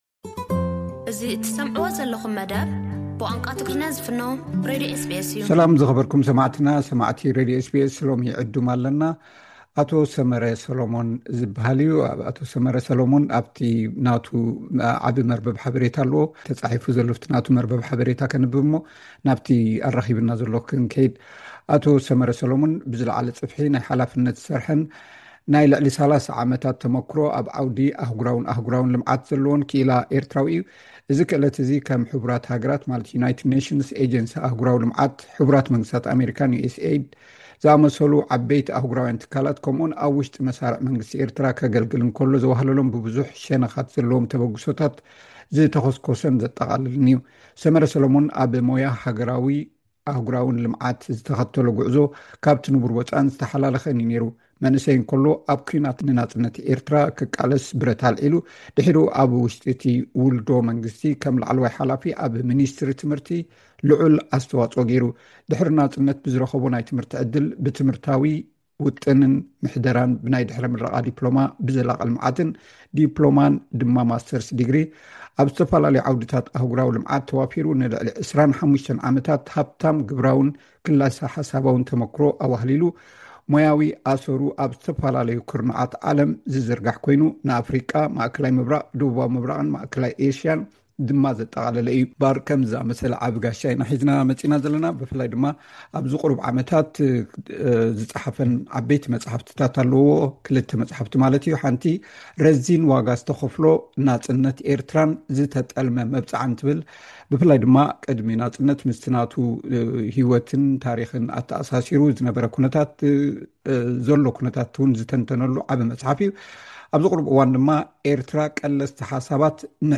ሰፊሕ ቃለ መሕትት ቀዳማይ ክፋል ።